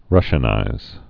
(rŭshə-nīz)